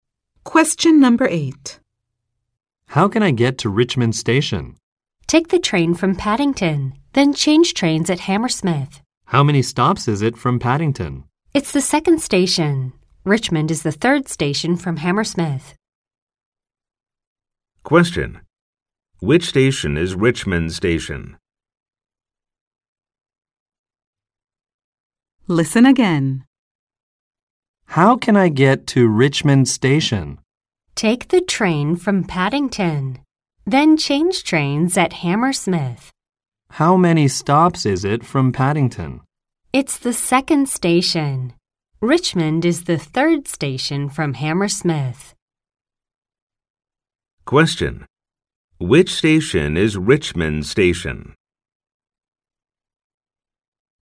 〇アメリカ英語に加えて、イギリス英語、アジア英語の話者の音声も収録しています。
〇早口で実録音したハイスピード音声をダウンロードにて提供。
ノーマル・スピード音声   ハイ・スピード音声